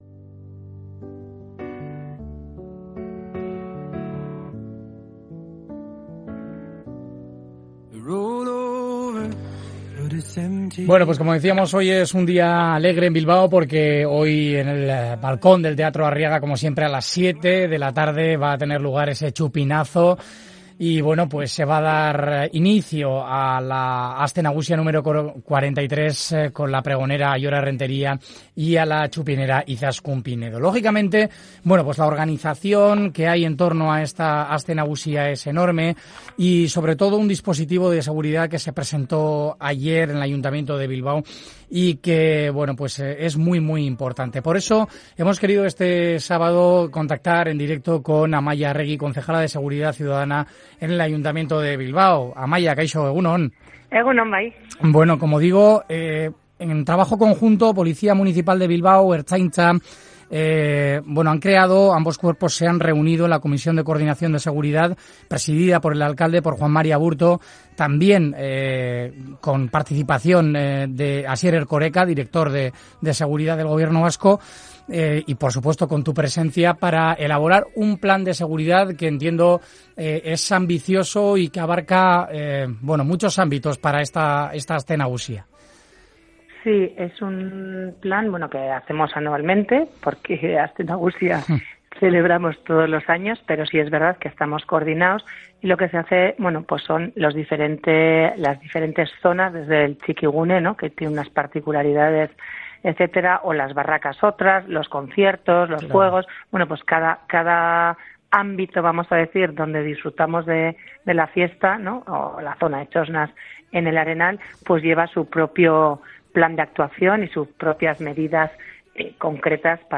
Amaia Arregi, concejala de seguridad, nos ha detallado en Onda Vasca las medidas de prevención para evitar hurtos de carteras y móviles, que el año pasado estuvieron detrás del 72% de las denuncias que se recibieron.